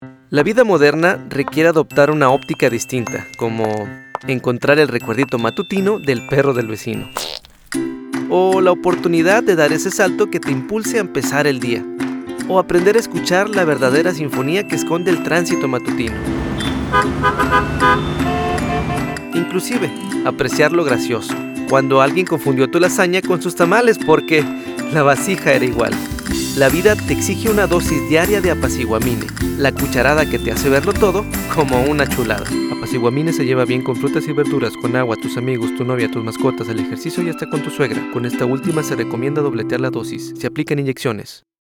Spanish voice Natural Conversational
Sprechprobe: Werbung (Muttersprache):